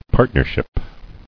[part·ner·ship]